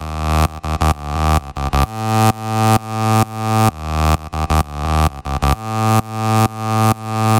电音屋基音侧链
标签： 65 bpm Electro Loops Bass Wobble Loops 1.24 MB wav Key : Unknown
声道立体声